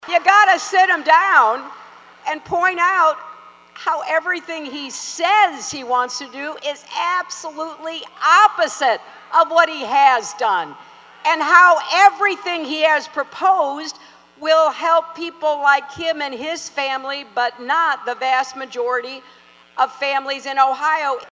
Clinton drew about 2,600 to a rally in Akron, about a week before early voting begins in Ohio.